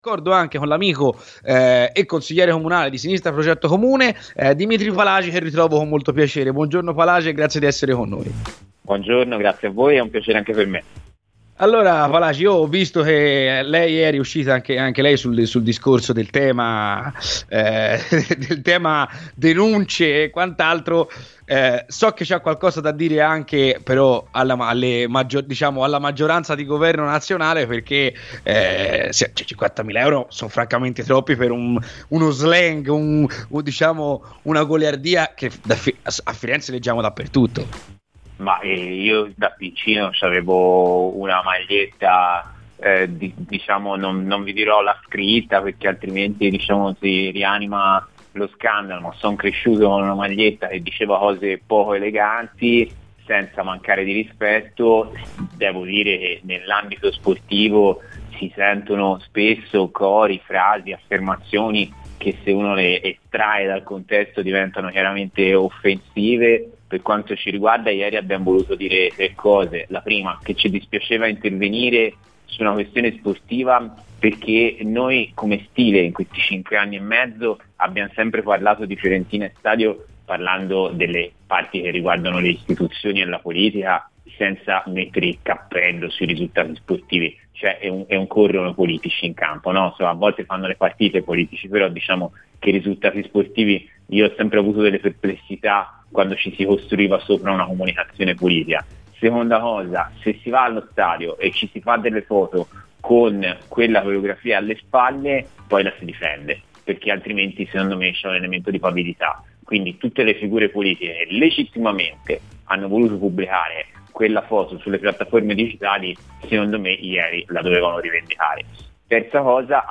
Dimitri Palagi, Consigliere Comunale di Sinistra Progetto Comune è intervenuto ai microfoni di Radio FirenzeViola durante la trasmissione "C'è Polemica".